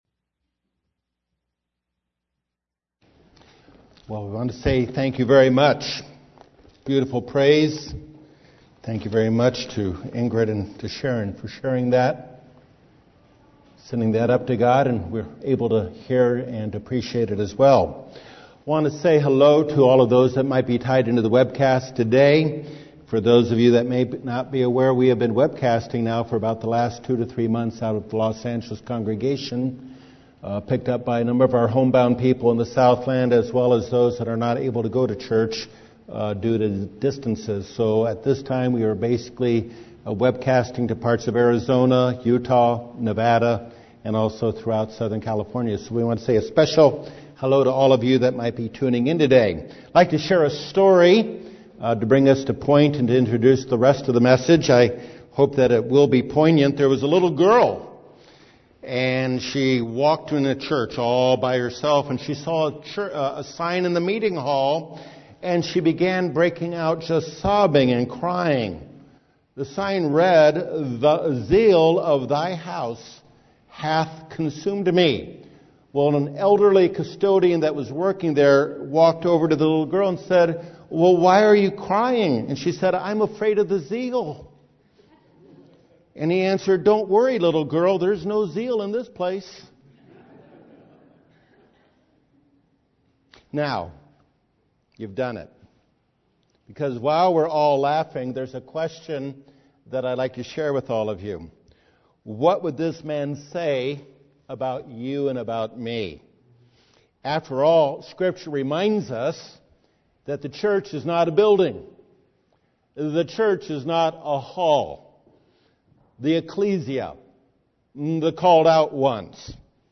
This message addresses how to obtain and sustain the zeal toward God's calling.